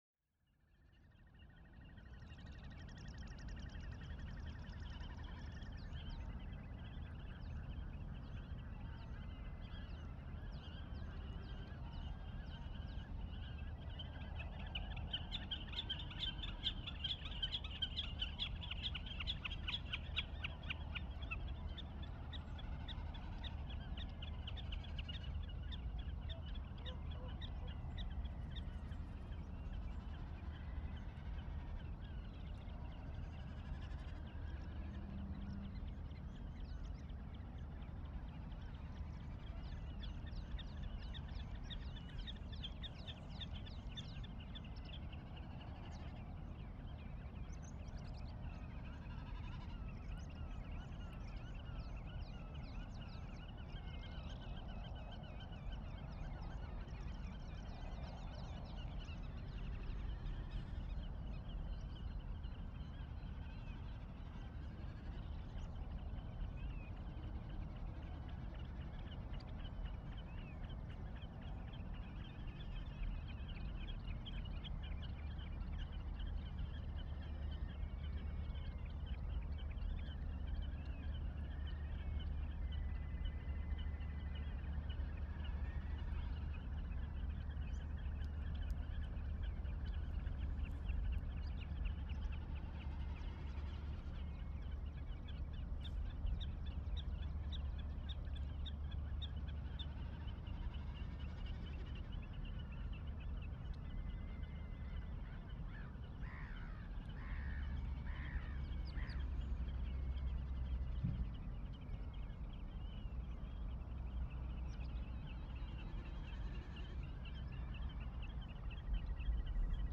Recorder: Sound devices MixPre6 Mics: Lewitt LCT540s, NOS 30cm/90° Pix: Canon EOS R
I went there on the 3rd of July 2021 and recorded overnight. The weather was calm in the beginning but the wind increased over the night. I was also struggling with increased sea tide and therefore a surf noise from the coast line, actually at the same time when the birds were most active during the dawn. My mics were Lewitt LCT540s in IRT cross setup. Most of the bird’s activity was in front of channel 1&2. Most „silence“ was in front of channel 2&3 and most of the surf and traffic noise was at channel 4&1.
There was almost constant rumble in the air during the ten hours I was recording, except between four and five o’clock in the morning.